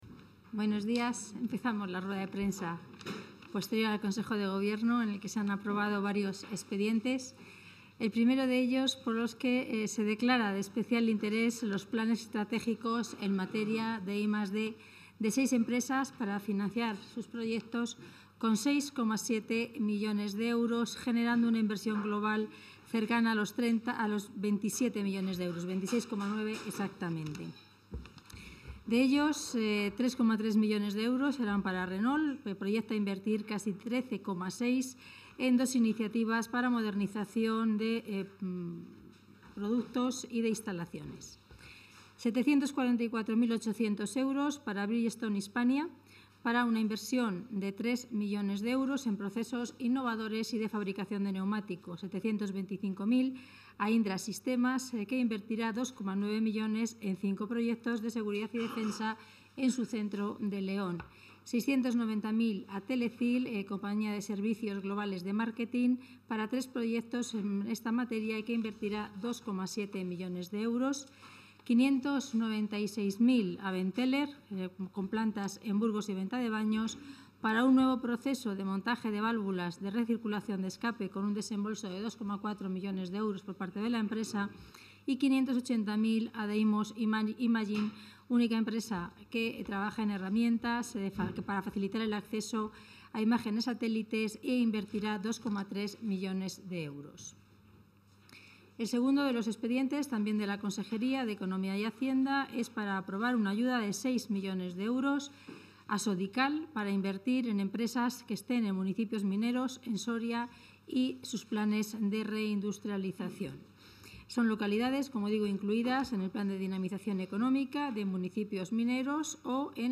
Rueda de prensa posterior al Consejo de Gobierno.